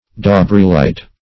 Search Result for " daubreelite" : The Collaborative International Dictionary of English v.0.48: Daubreelite \Dau"bree*lite\, n. [From Daubr['e]e, a French mineralogist.]
daubreelite.mp3